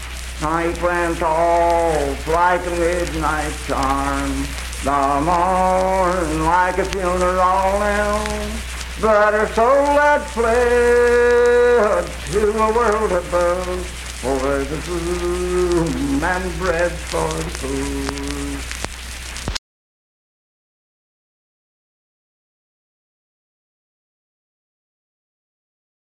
Unaccompanied vocal music performance
Verse-refrain fragment.
Voice (sung)